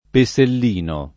vai all'elenco alfabetico delle voci ingrandisci il carattere 100% rimpicciolisci il carattere stampa invia tramite posta elettronica codividi su Facebook Pesellino [ pe S ell & no ] soprann. (del pittore Francesco di Stefano, 1422-57)